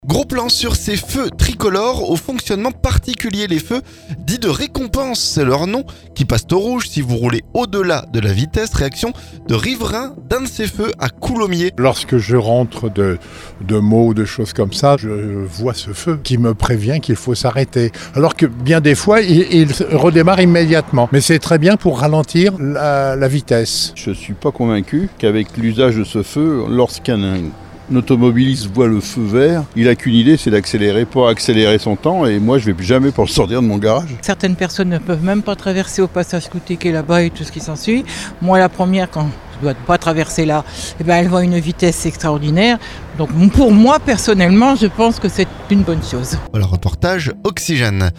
Réaction de riverains d'un de ces feux à Coulommiers.